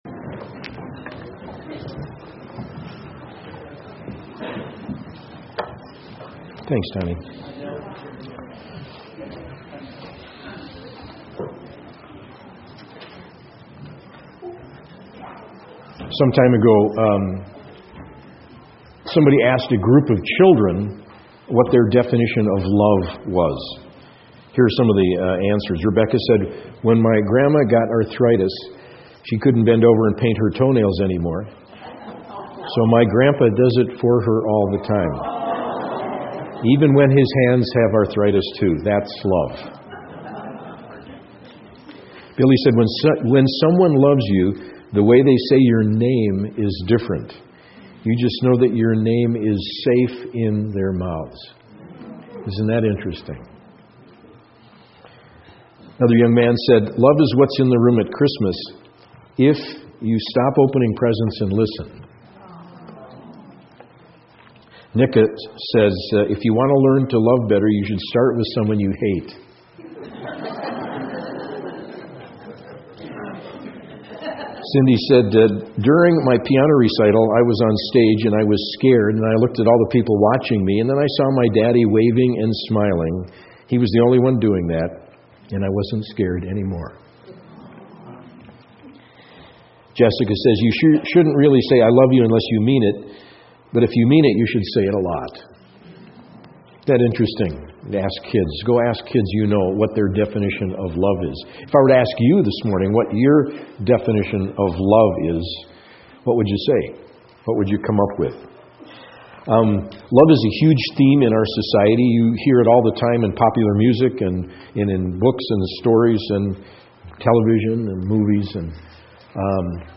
SERMONS The Importance of Love The Greatest of These...Love from 1 Corinthians 13 September 30